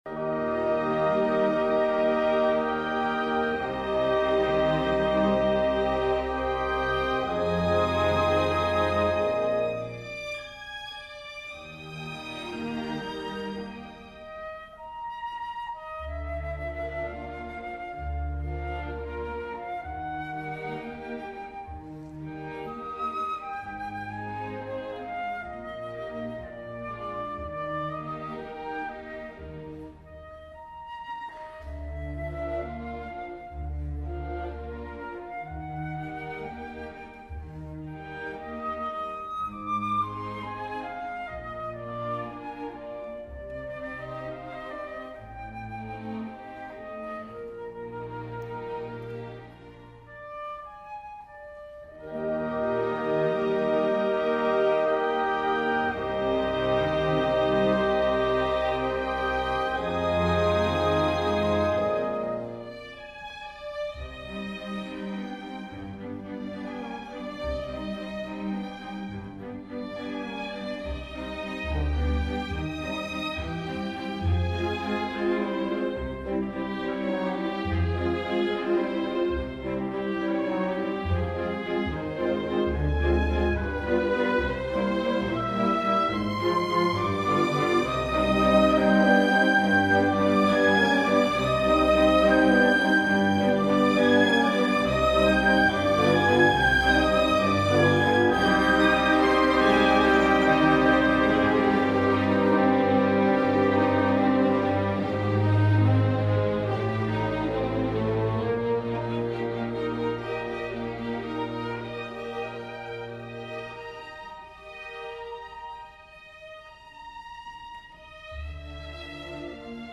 Full Orchestra , Chamber Orchestra